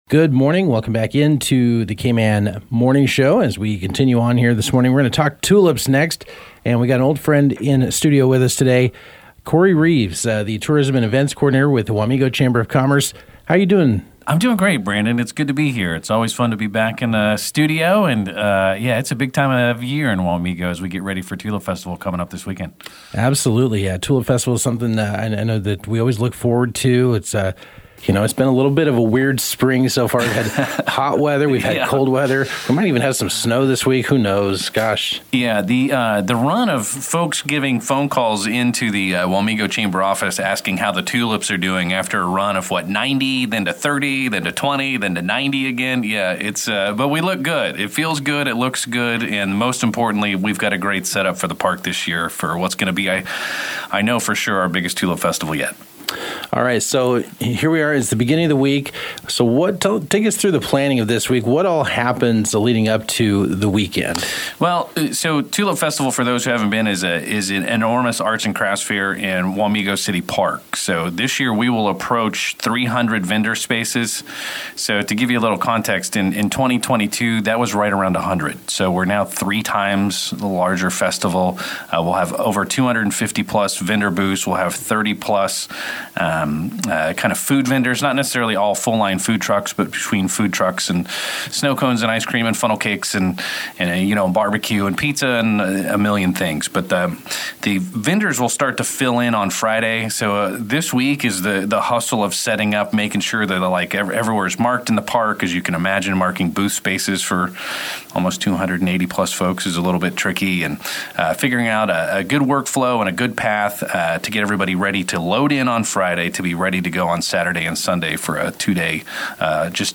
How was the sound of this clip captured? stopped by our studios to preview the annual community event.